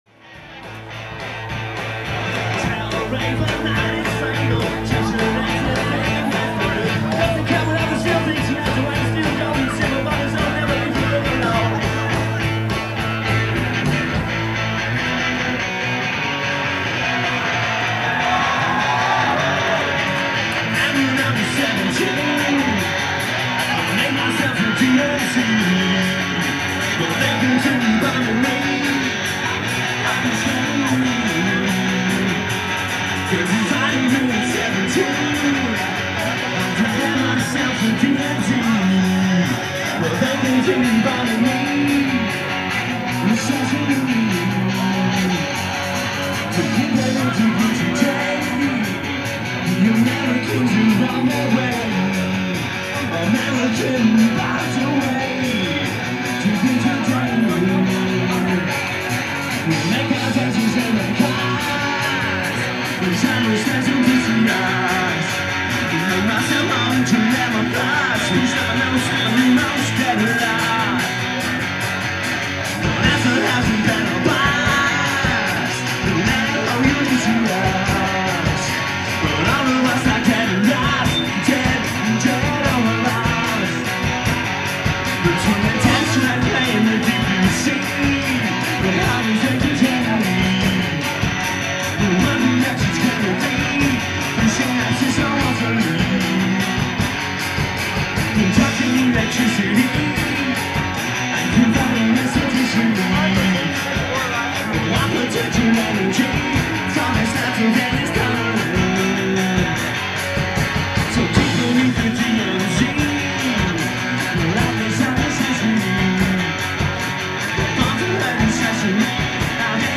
live at the Middle East, Cambridge, MA